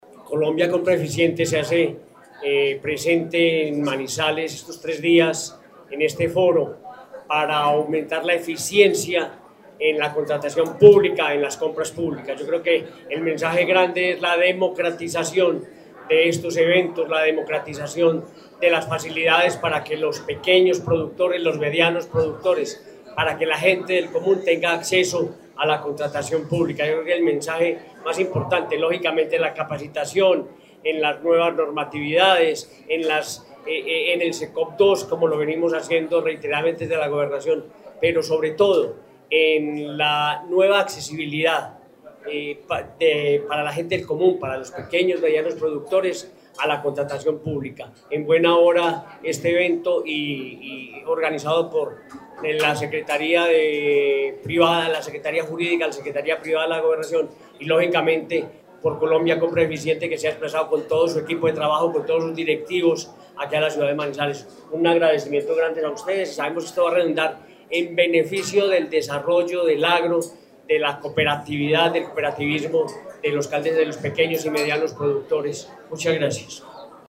Con la presencia del director general de la Agencia Nacional de Contratación Pública-Colombia Compra Eficiente, Cristóbal Padilla Tejeda, y el gobernador de Caldas, Henry Gutiérrez Ángel, se instaló en Manizales el Foro de Contratación Estatal y Compras Públicas.
Gobernador de Caldas, Henry Gutiérrez Ángel.
Gobernador-de-Caldas-Henry-Gutierrez-Angel.mp3